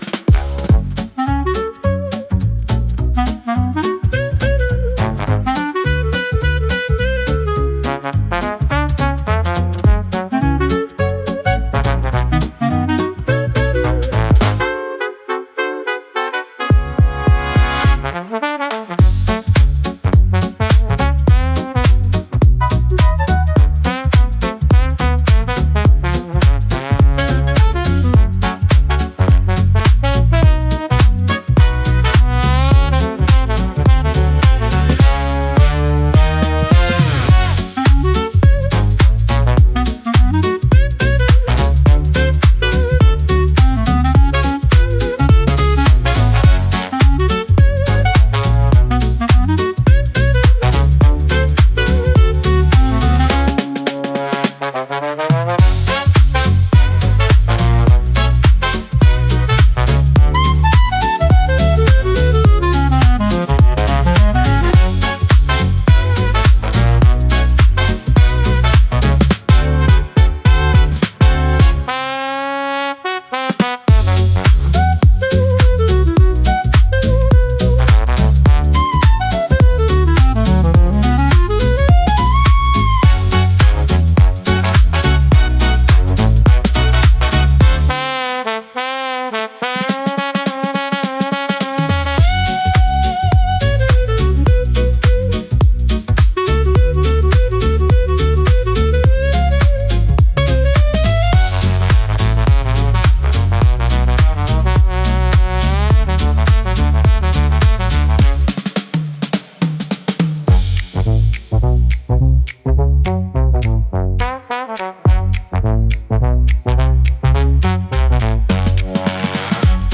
イメージ：インスト,エレクトロ・スゥイング,ジャズ
インストゥルメンタル（instrumental）